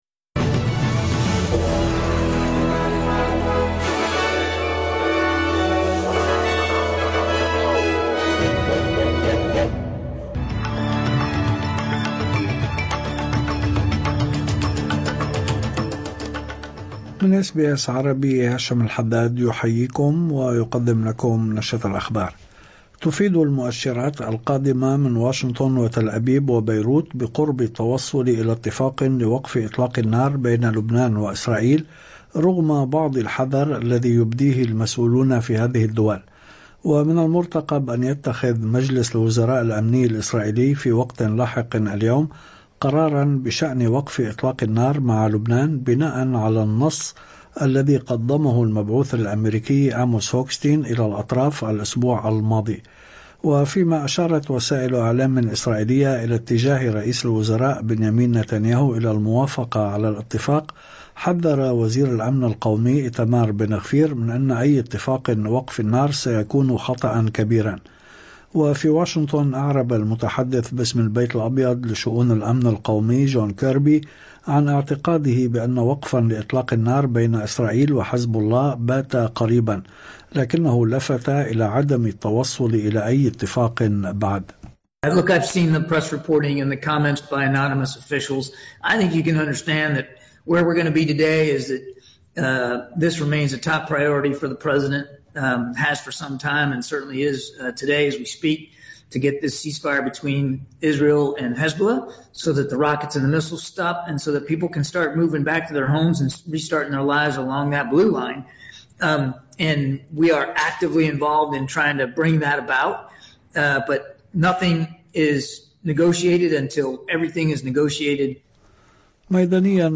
نشرة أخبار الظهيرة 26/11/2024